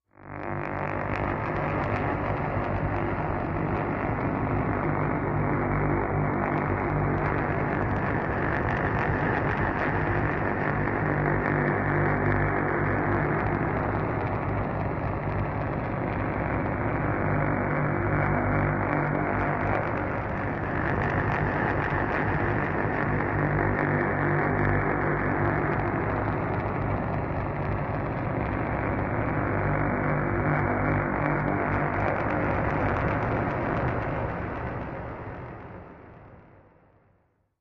Ghost Planes Distant War Plane Ambience Electric Props